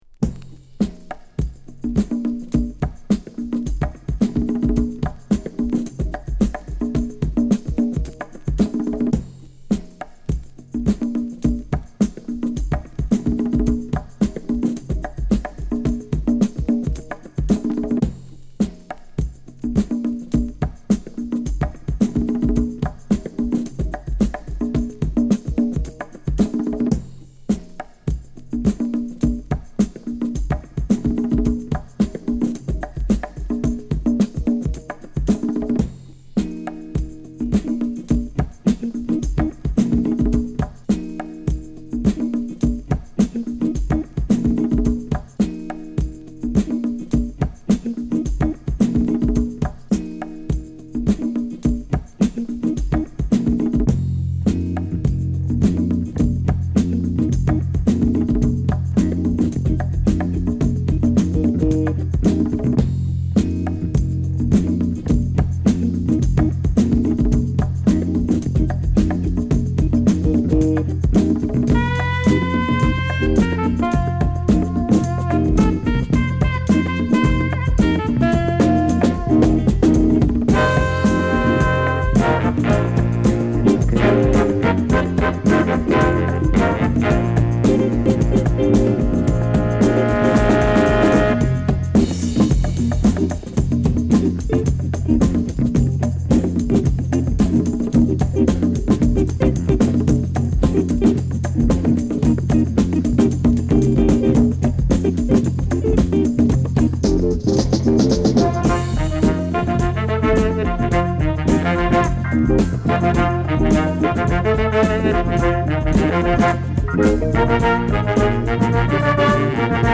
パーカッシヴなドラム・ブレイク、お馴染みのベースライン、ビターなギター
エロティックなコーラスが一体となって展開する、永遠不滅のエロ・ファンク。